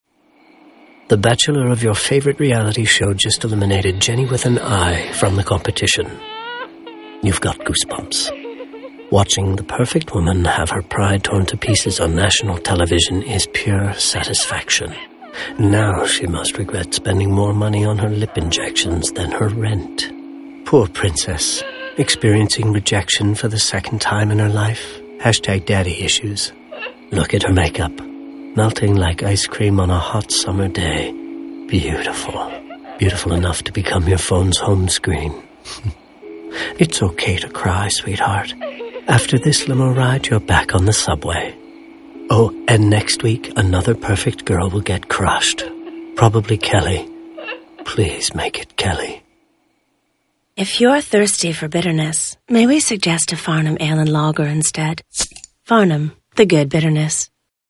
Sound design : BLVD